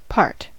part: Wikimedia Commons US English Pronunciations
En-us-part.WAV